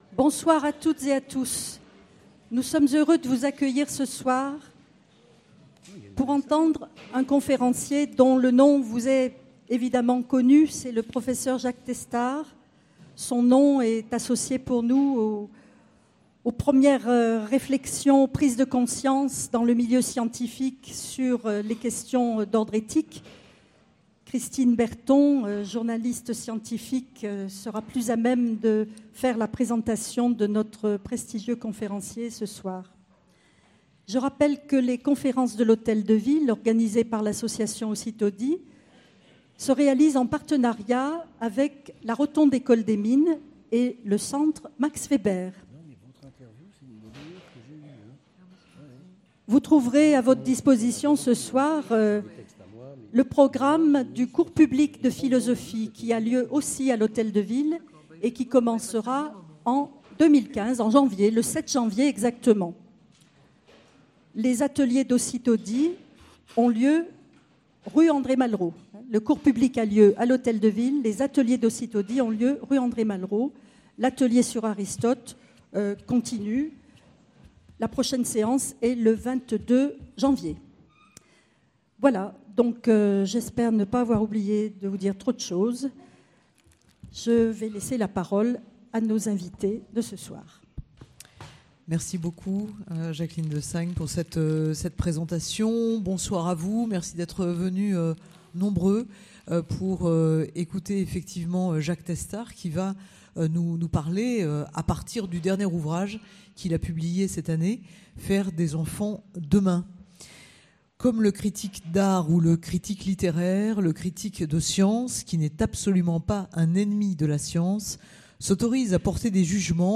Enregistrement de la conférence du 9 décembre 2014